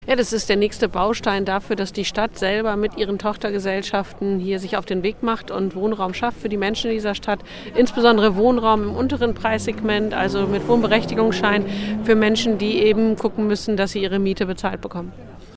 Gestern wurde im nordöstlichen Zipfel des Landwehrviertels Richtfest für zwei Mehrfamilienhäuser gefeiert. Oberbürgermeisterin Katharina Pötter freute sich über den Fortschritt und sagte im OS-Radio Interview: